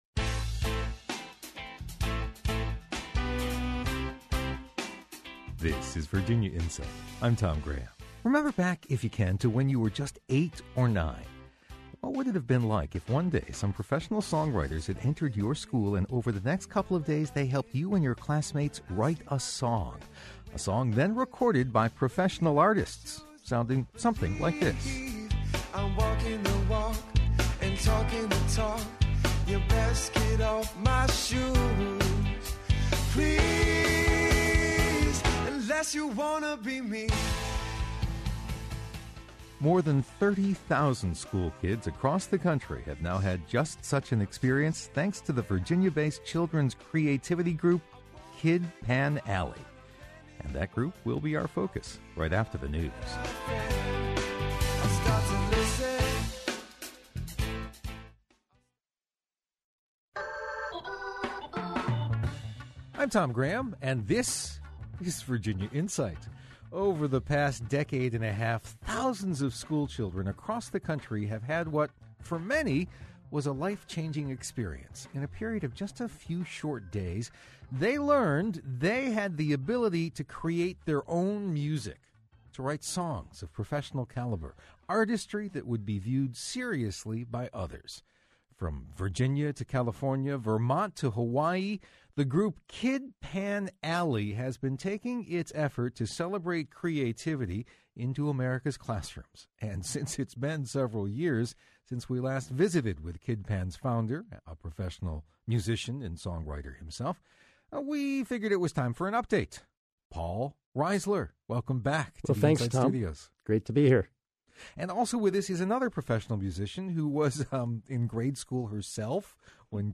WMRA-Interview-June-2013-pt_1.mp3